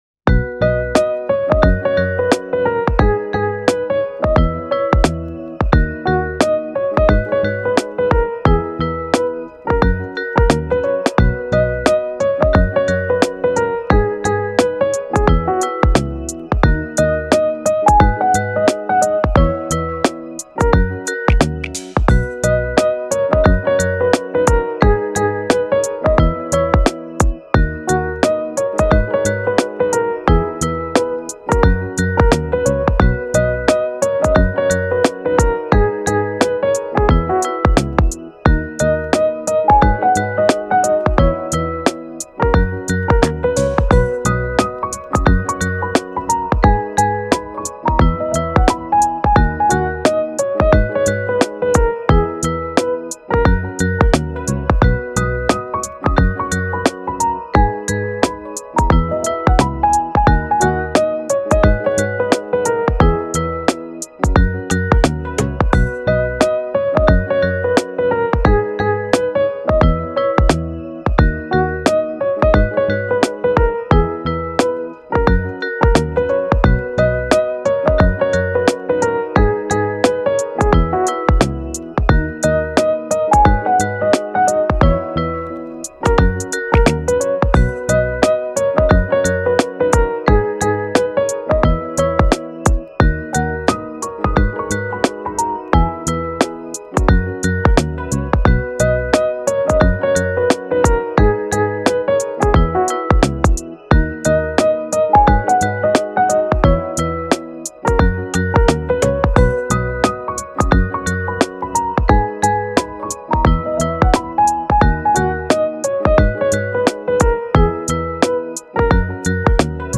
フリーBGM
チルポップ , ローファイ , 可愛らしい , 心地良い